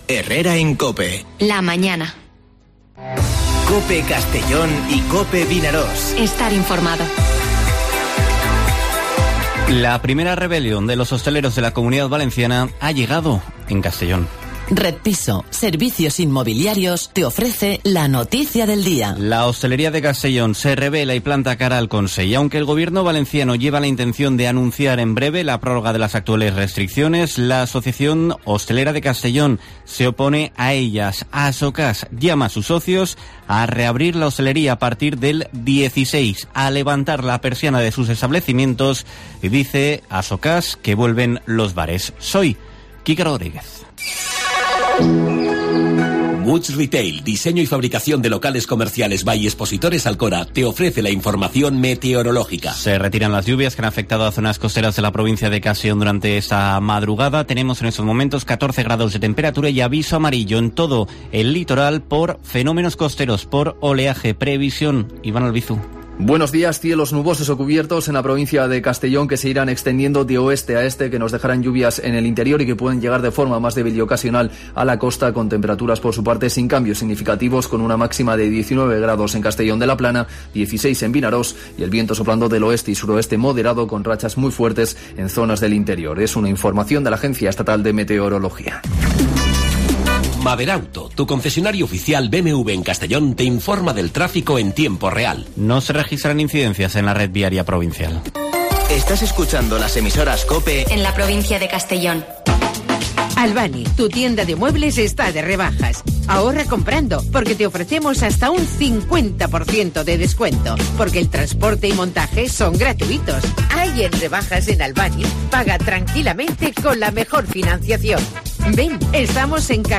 Informativo Herrera en COPE en la provincia de Castellón (09/02/2021)